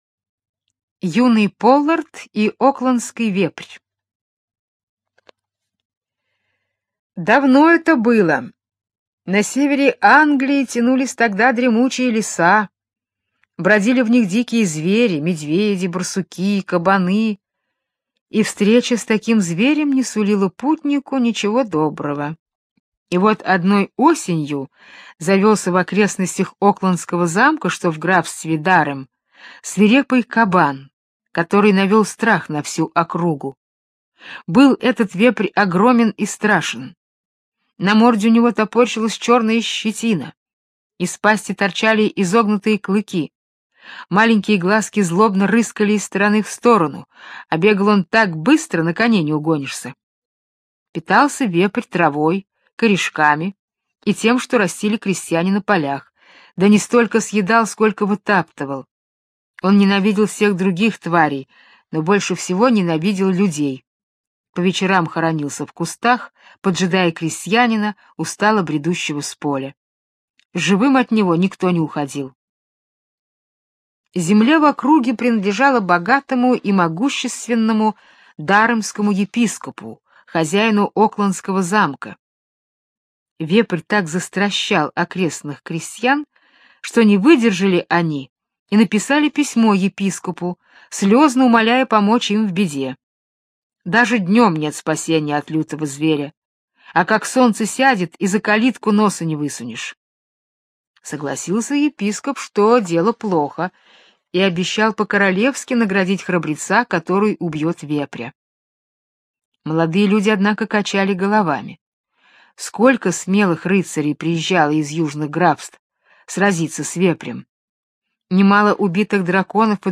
Слушайте Юный Поллард и окландский вепрь - британская аудиосказка. Про храброго юношу Полларда, который решился истребить страшного вепря.